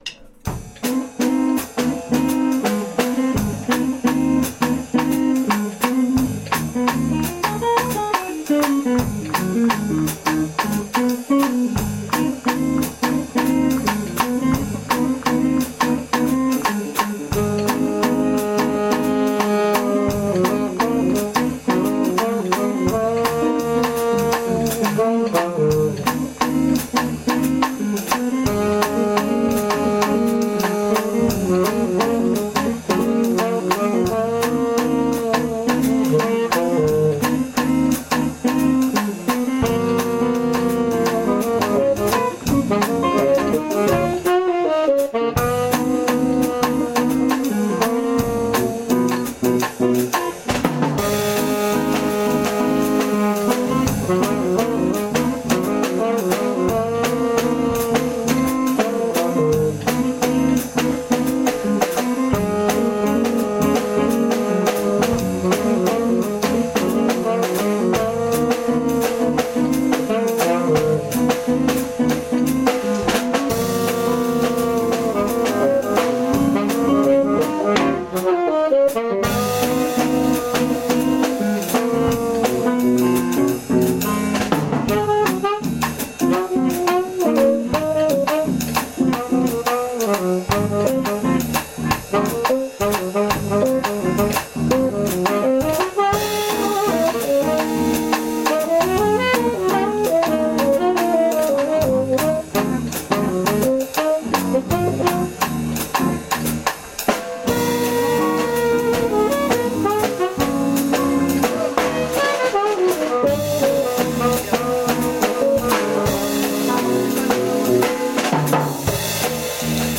Style funk, fusion